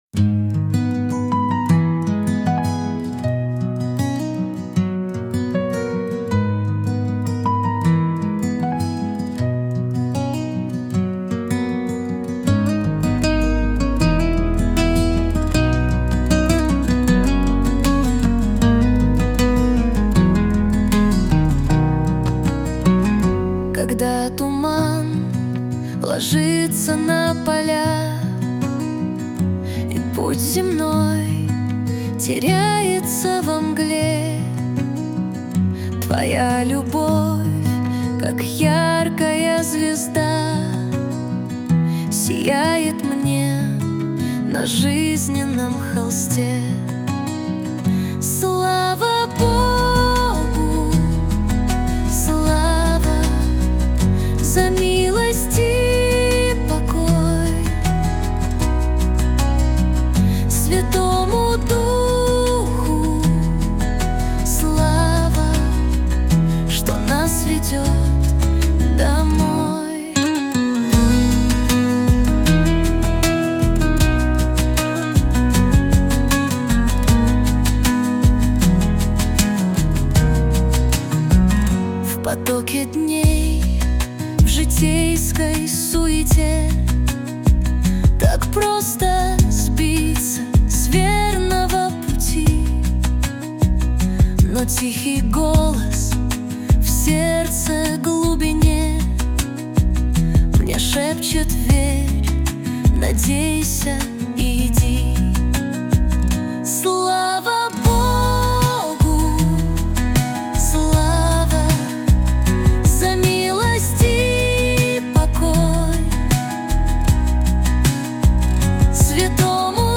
песня ai
122 просмотра 679 прослушиваний 45 скачиваний BPM: 79